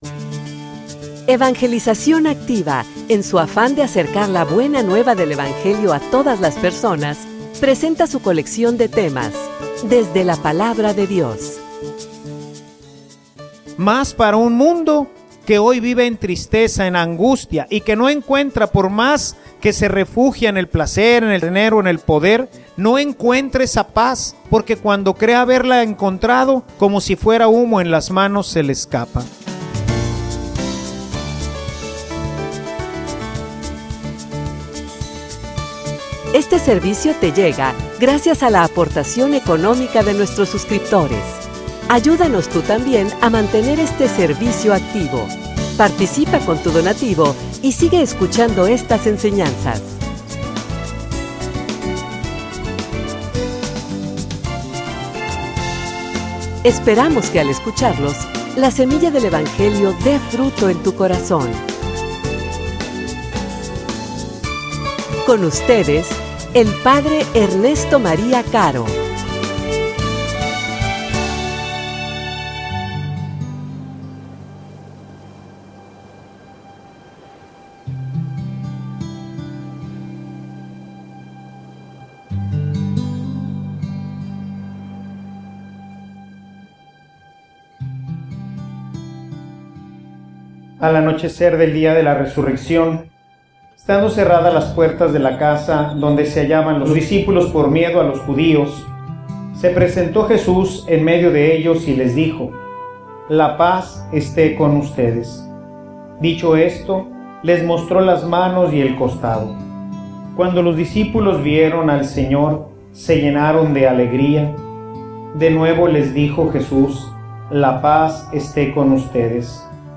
homilia_Misericordia_que_se_convierte_en_rescate.mp3